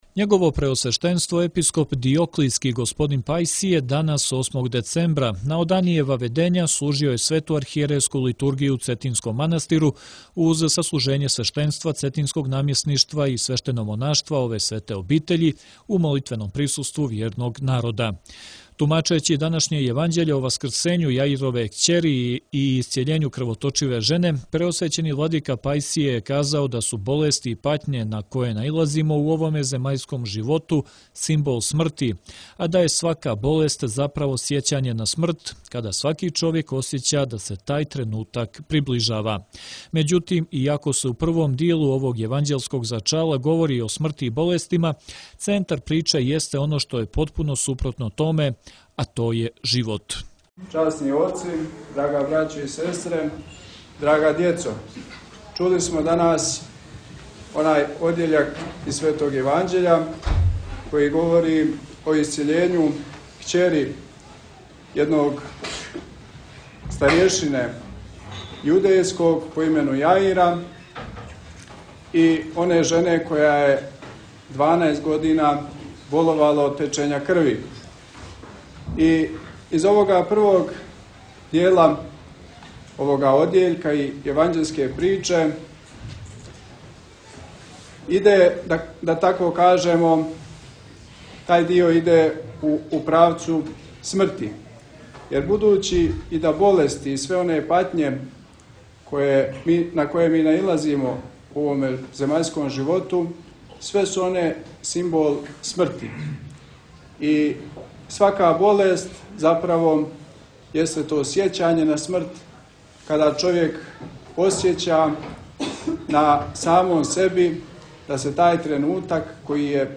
Његово преосвештенство Епископ диоклијски г. Пајсије данас, 8. децембра, на Оданије Ваведења, служио је Свету архијерејску литургију у Цетињском манастиру, уз саслужење свештенства цетињског намјесништва и свештеномонаштва ове свете обитељи, у молитвеном присуству вјерног народа.
08.12-Vladika-Pajsije-Cetinje.m4a